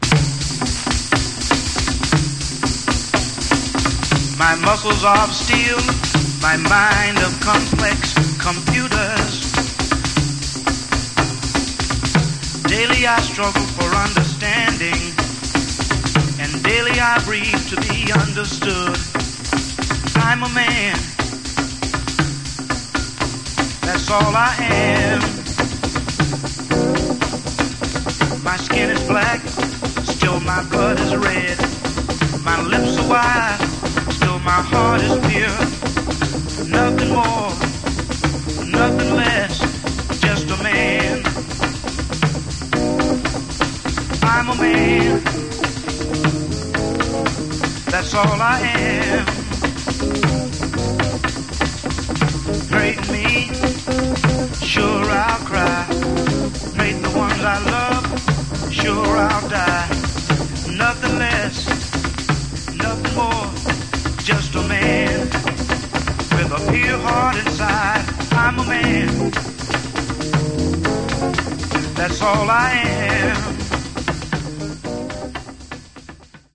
Genre: Northern Soul, Motown Style